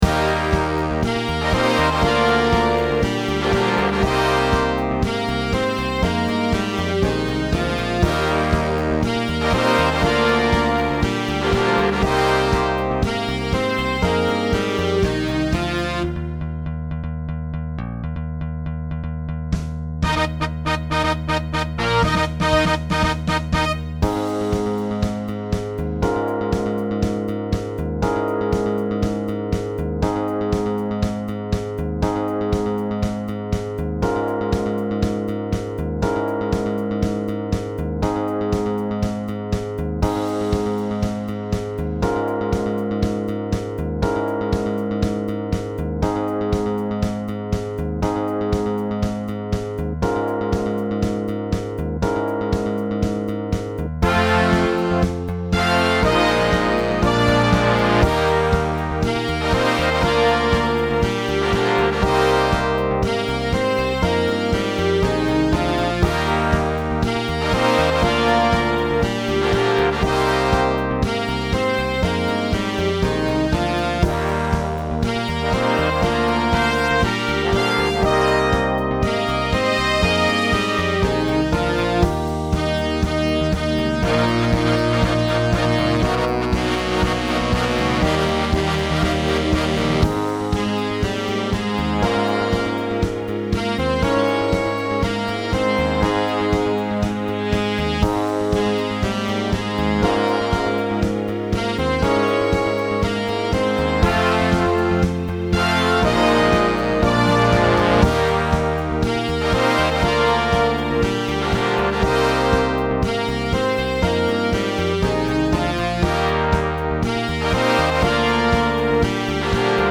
Add to cart Category: Big Band Vocal